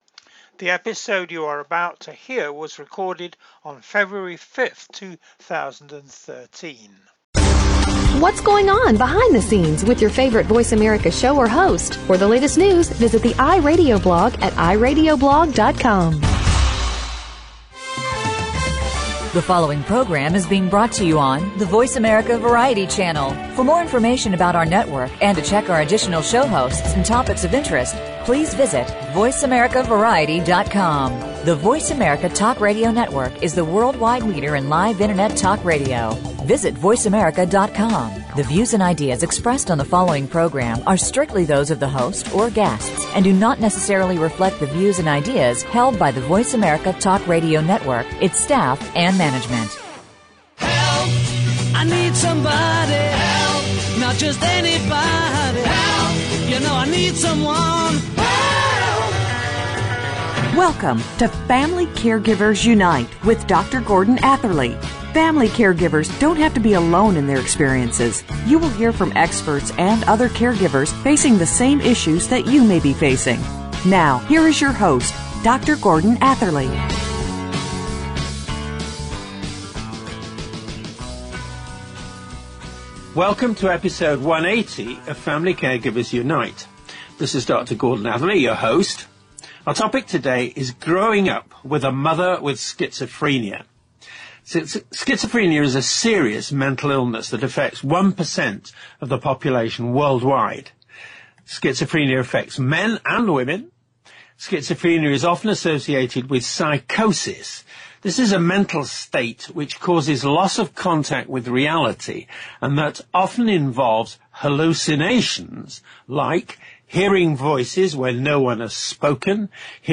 It empowers by giving voice to individuals living with schizophrenia, to their family caregivers and families, and to their healthcare and social services providers. They describe their experiences in their own voices. Their voices are broadcast on internet radio.